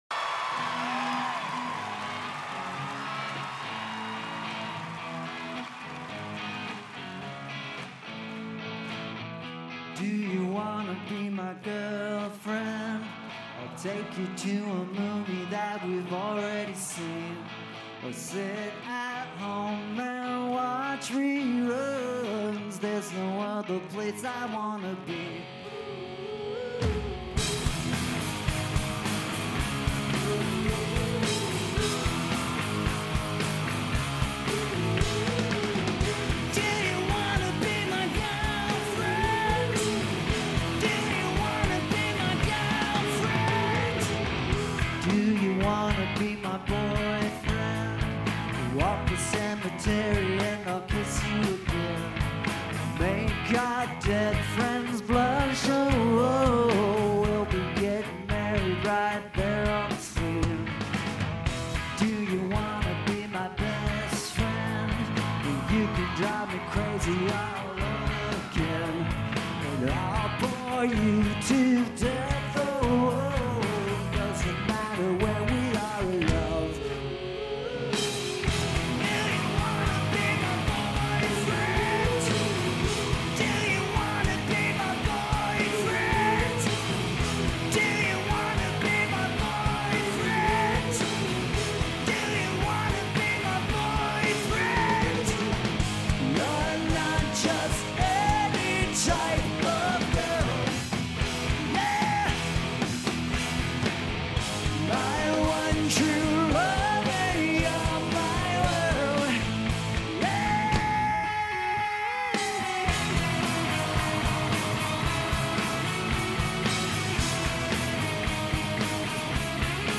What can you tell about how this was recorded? live in HD